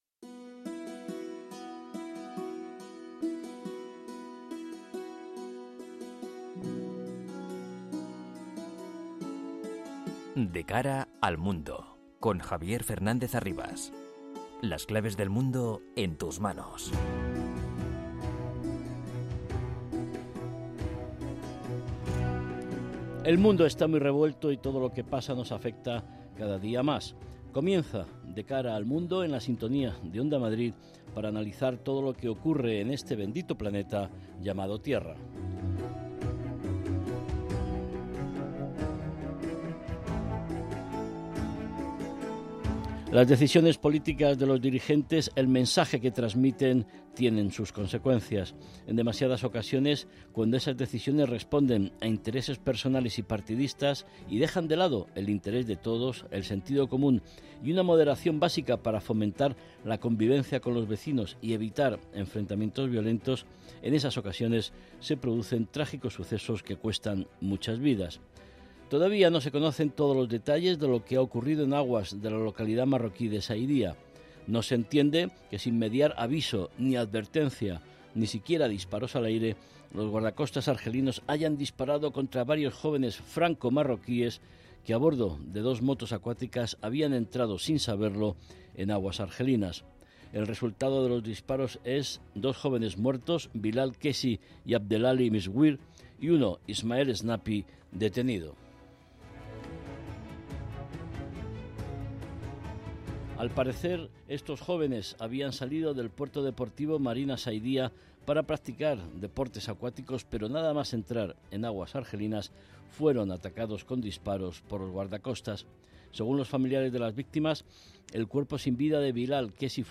entrevistas a expertos y un panel completo de analistas